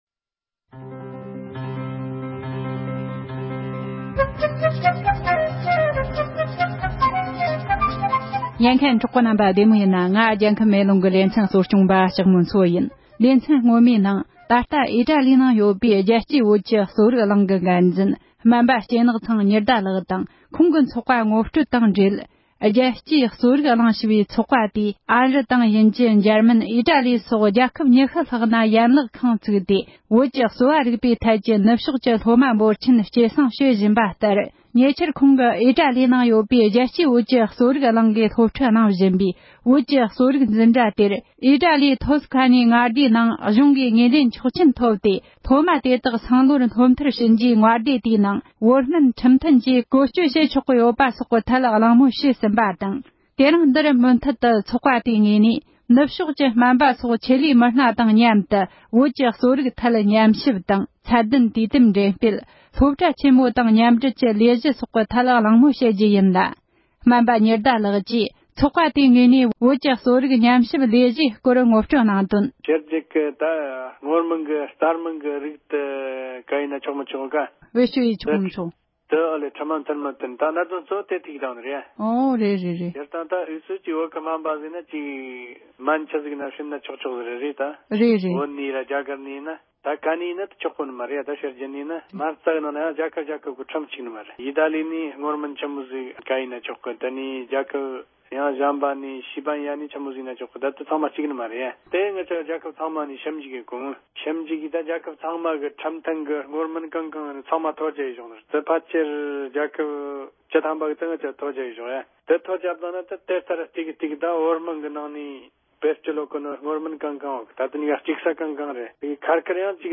ཨོ་སི་ཊེ་ལི་ཡའི་ནང་ཡོད་པའི་རྒྱལ་སྤྱིའི་བོད་ཀྱི་གསོ་རིག་གླིང་གི་སྐོར་གླེང་མོལ་ཞུས་པའི་ལེ་ཚན་གཉིས་པ།